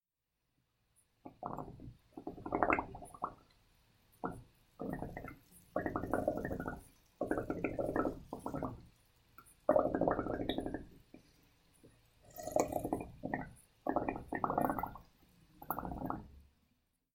Bubbles » Bubbles Descend
描述：Short Bubbles descending into glass of water
标签： Air Descend Bubbles
声道立体声